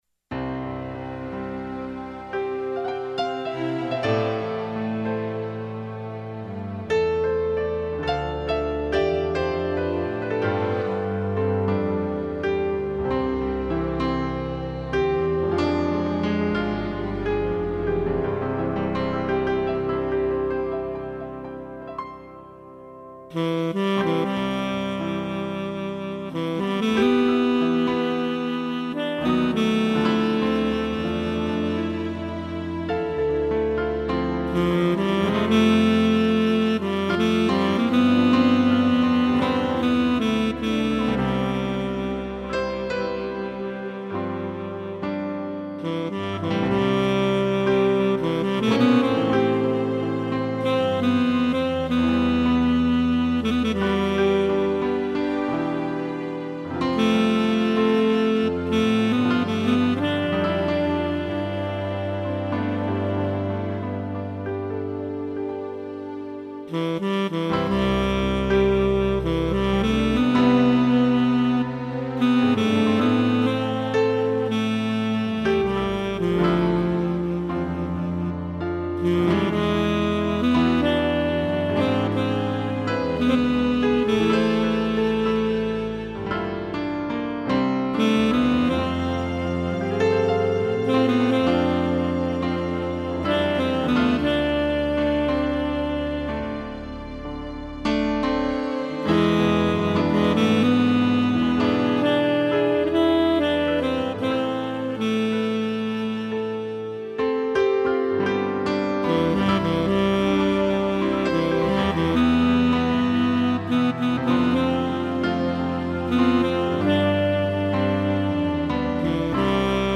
piano e strings